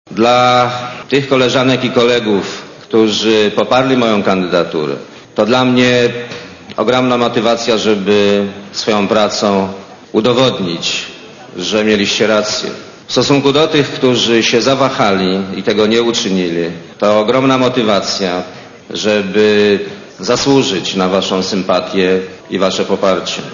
Mówi Leszek Miller (92Kb)